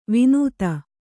♪ vinūta